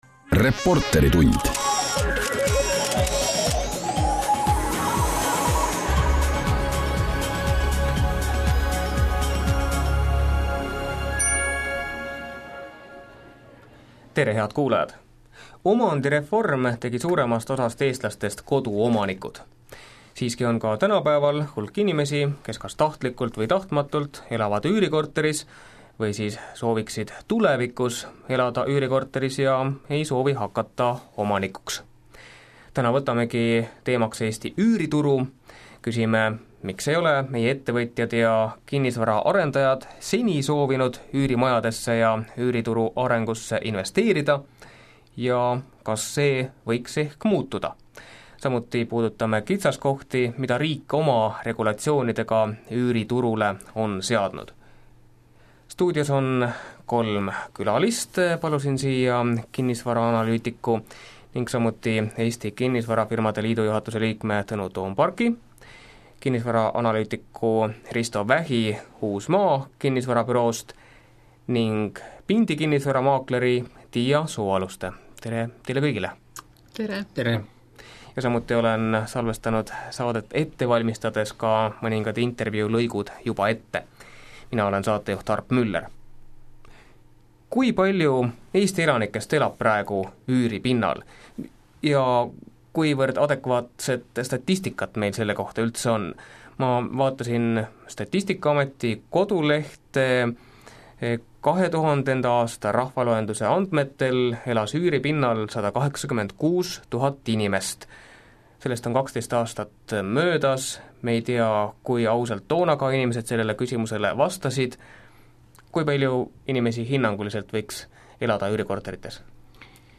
Käsitlesime 30/01/2012 raadiosaates Reporteritund üüriturul toimuvat.